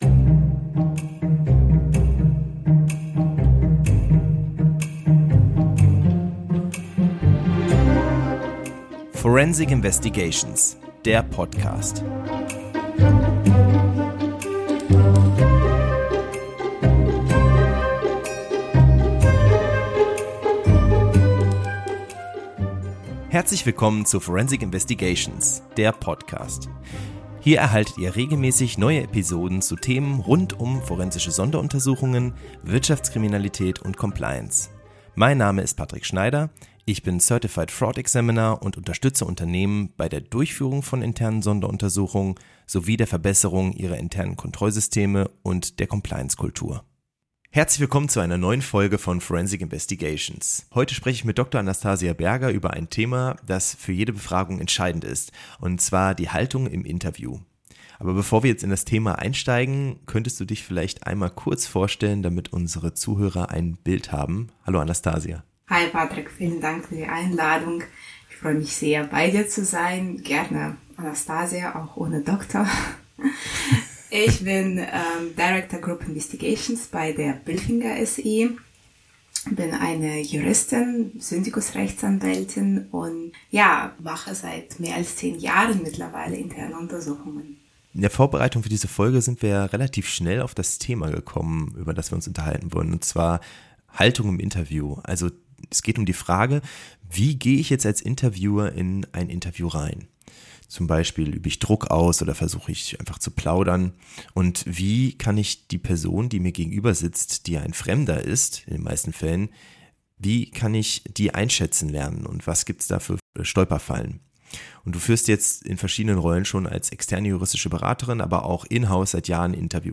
Ein ehrlicher Austausch über Professionalität, Empathie und die feinen Grenzen zwischen Nähe und Distanz.